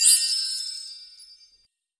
chimes_magical_bells_04.wav